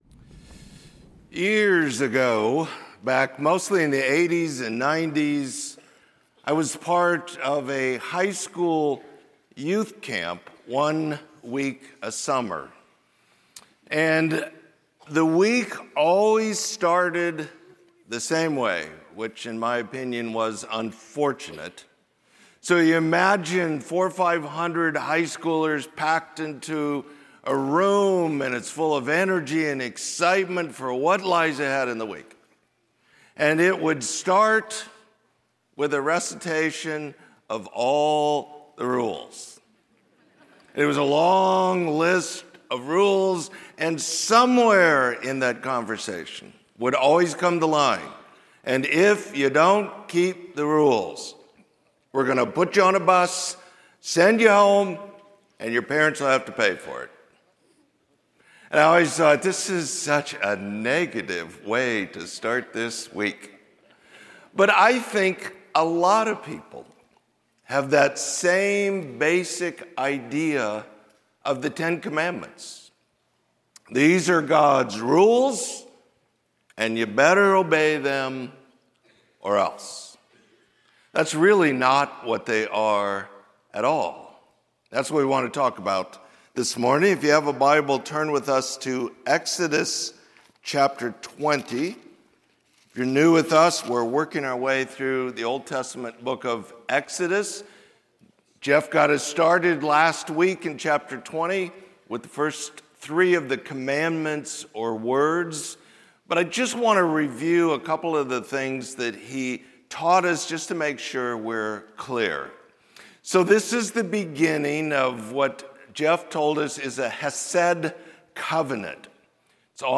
Sermon: That You May Not Sin